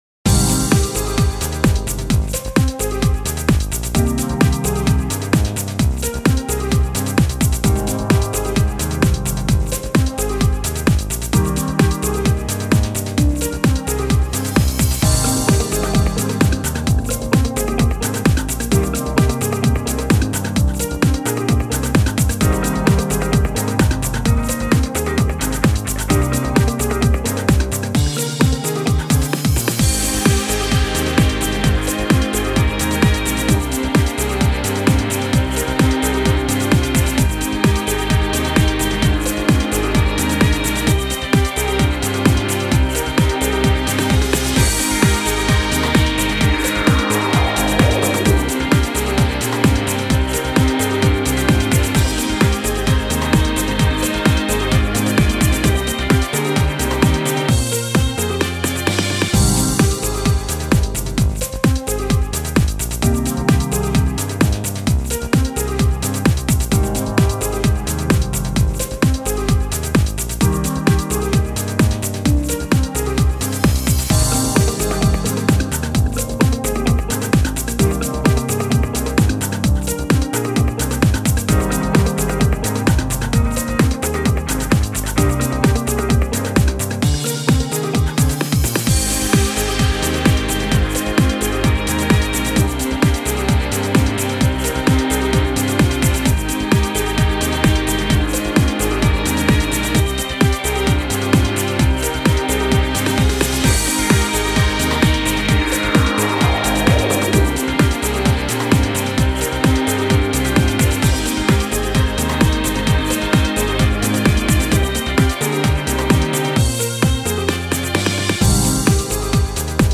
都会的な雰囲気のインスト曲です。クールで落ち着いたムードがほしいときにどうぞ。ループするのとしないのと両方用意しました。